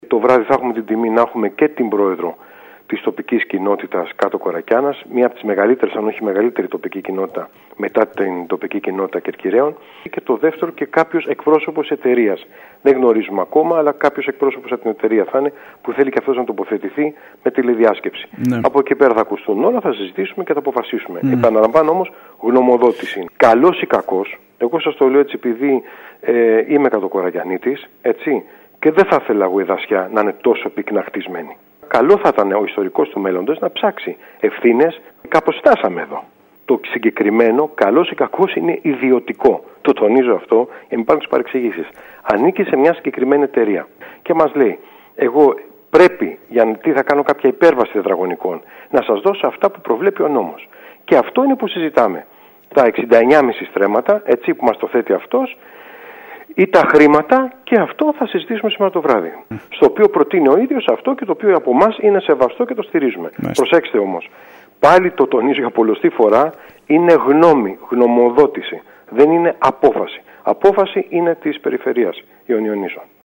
Όπως ανέφερε στο σταθμό μας ο πρόεδρος του Δημοτικού Συμβουλίου Δημήτρης Μεταλληνός, ο αρμόδιος Αντιδήμαρχος Μίμης Κατέχης, θα καταθέσει εισήγηση σύμφωνα με την οποία μπορεί να γίνει η αποδοχή των 2 εκ. ευρώ και ταυτόχρονα να υπάρξει προστασία των ρεμάτων αλλά και ενός δρόμου που θα διασχίζει την περιοχή.